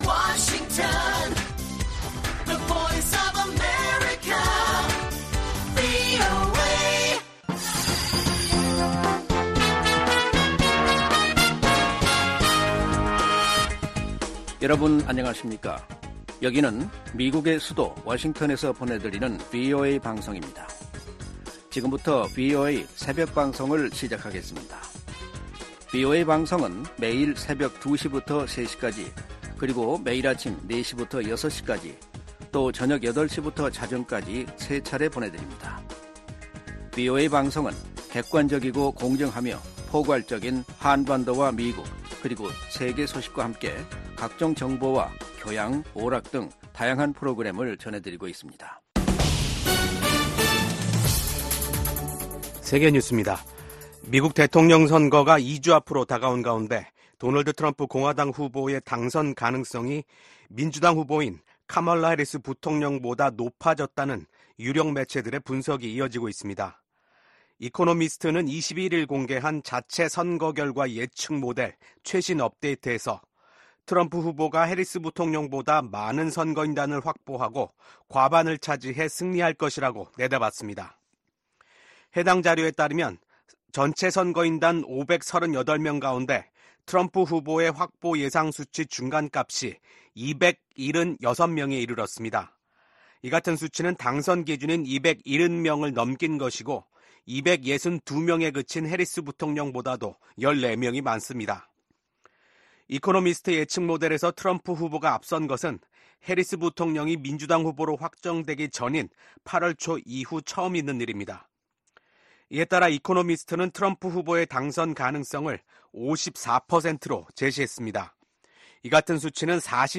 VOA 한국어 '출발 뉴스 쇼', 2024년 10월 23일 방송입니다. 미국 정부가 북한의 러시아 파병은 러시아 대통령의 절박함과 고림감이 커지고 있다는 증거라고 지적했습니다. 군축과 국제안보 문제를 다루는 유엔 총회 제1위원회 회의에서 북한의 핵∙미사일 프로그램 개발에 대한 우려와 규탄이 연일 제기되고 있습니다.